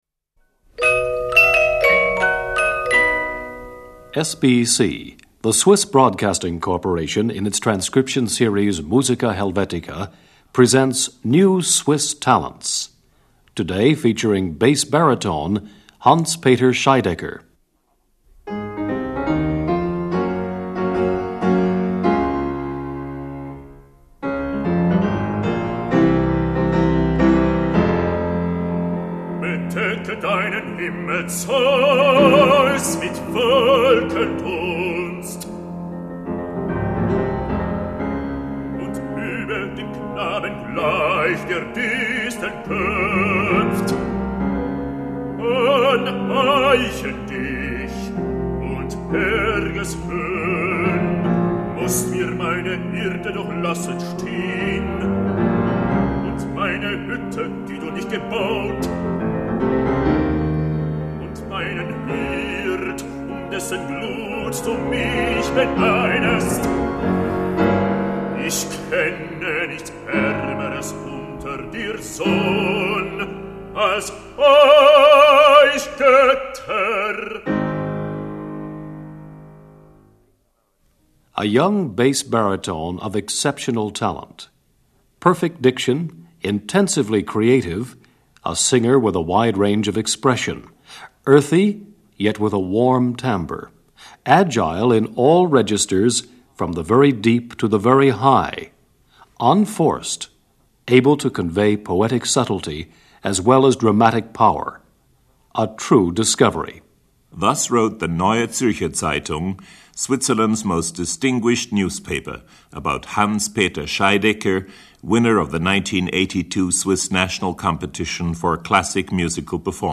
Bass Baritone
piano accompanist